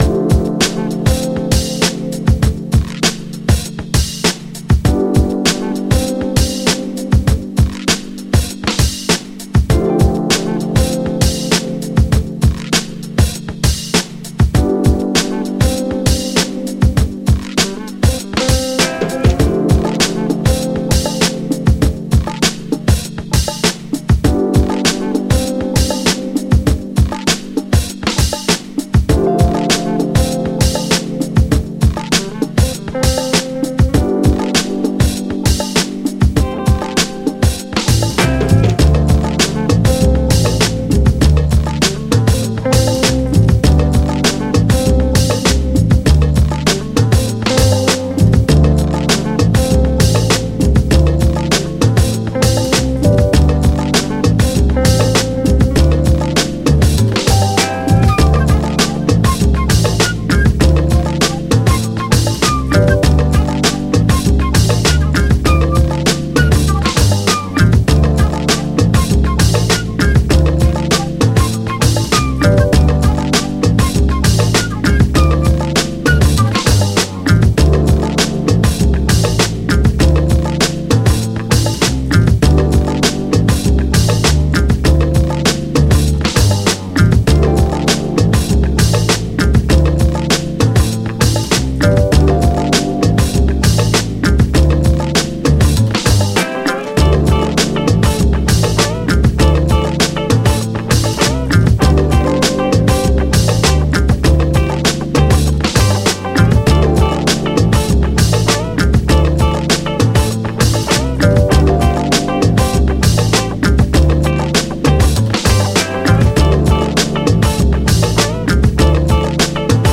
Out Here (даунтэмпо с гитарами, дудками и трубами)